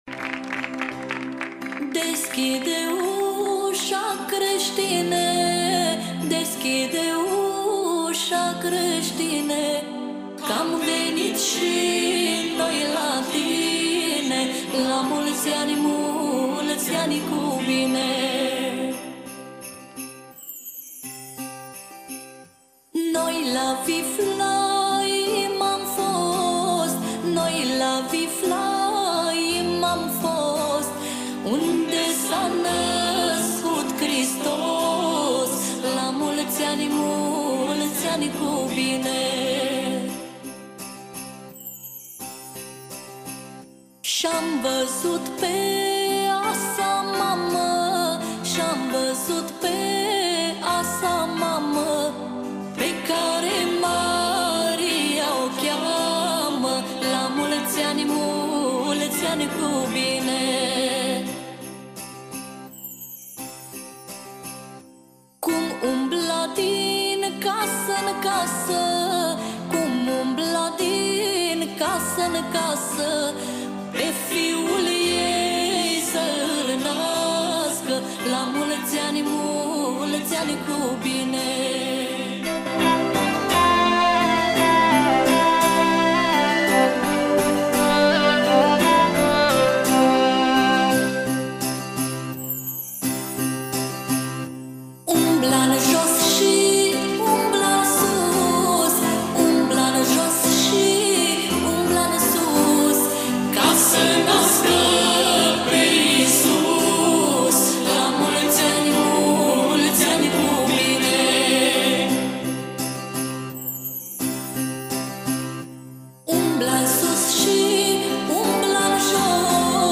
Categoria: Colinde Craciun